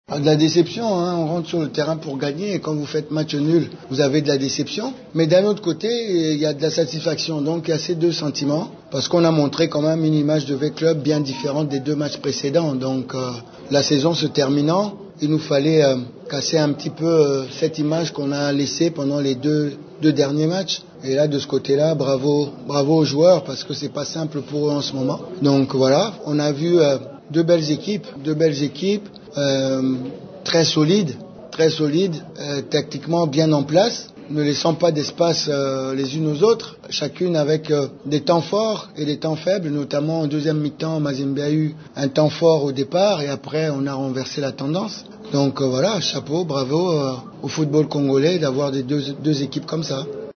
Un résultat qui laisse Florent Ibenge, coach de Vclub avec un sentiment partagé entre déception et joie, au regard des deux derniers résultats de son équipe.